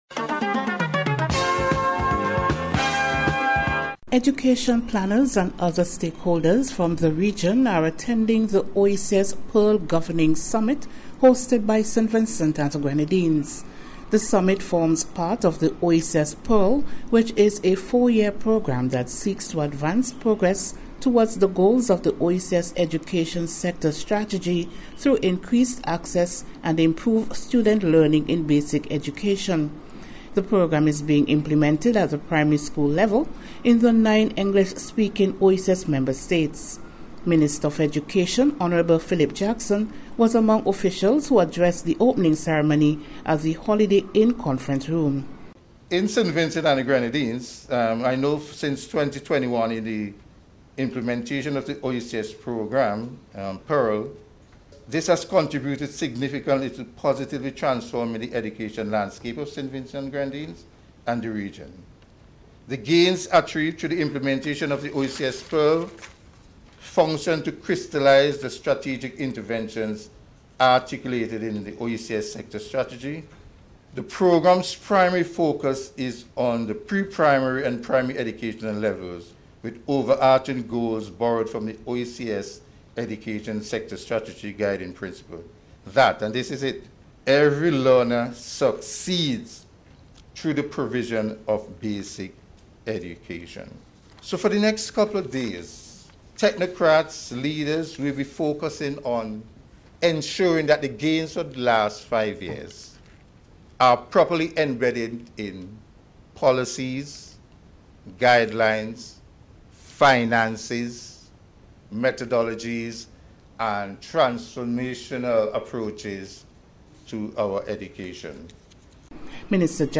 NBC’s Special Report- Wednesday 15th April,2026